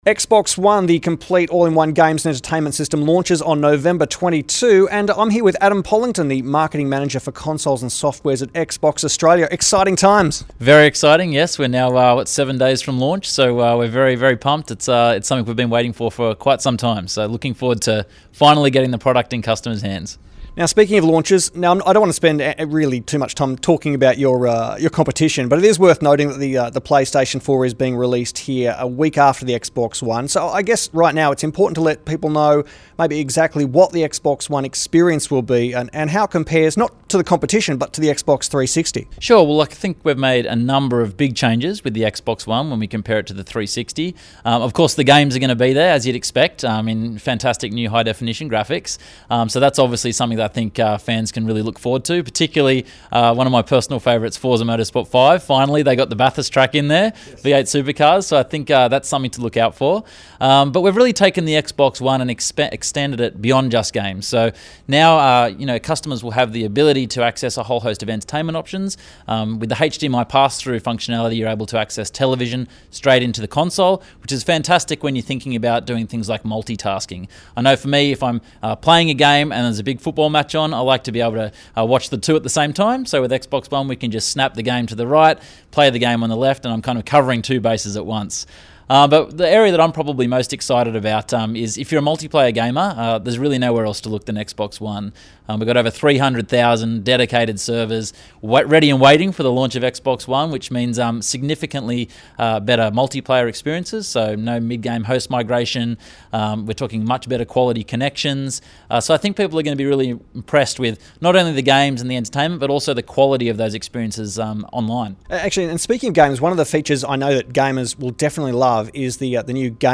XBox One launches this week – Interview with XBox Australia